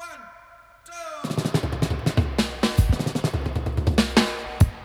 136-FILL-DUB.wav